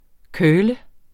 Udtale [ ˈkœːlə ]